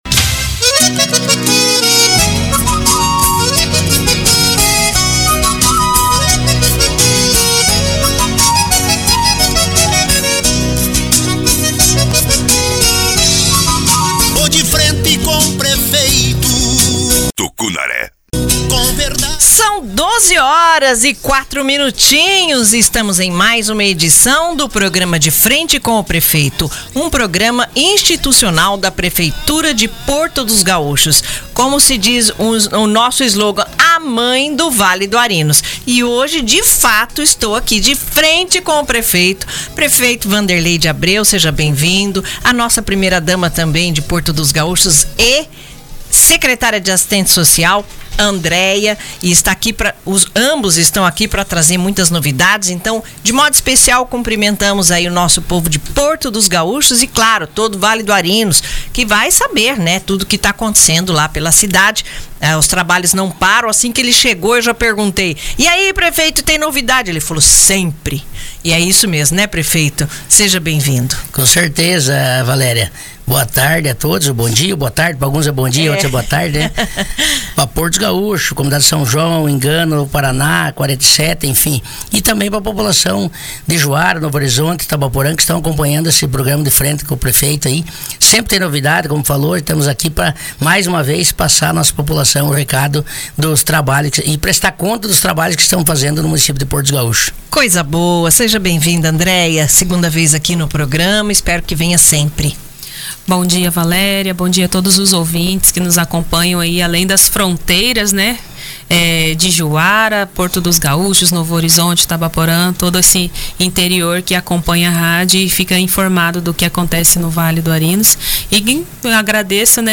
O município de Porto dos Gaúchos caminha para uma nova fase de desenvolvimento urbano e social, conforme anunciado pelo prefeito Vanderlei de Abreu e a primeira-dama e secretária de Assistência Social, Andréia, no programa institucional “De Frente com o Prefeito” do último dia 17.